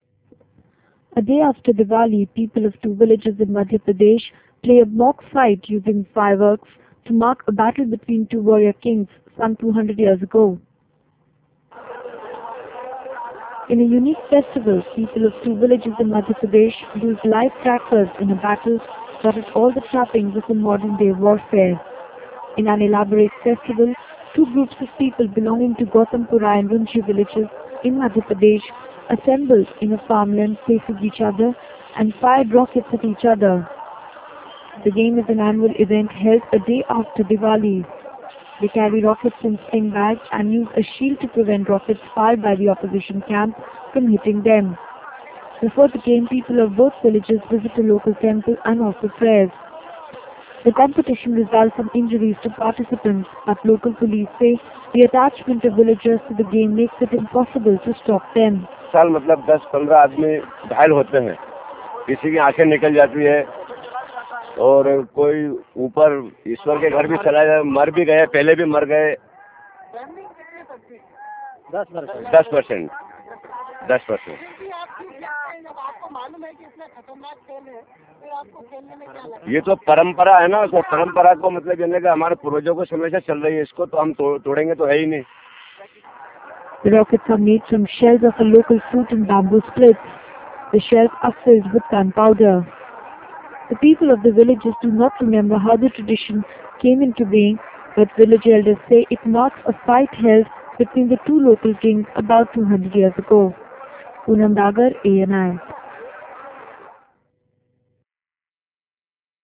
A day after Divali, people of two villages in Madhya Pradesh put up a mock fight using fireworks to mark a battle between two warrior kings some 200 years ago.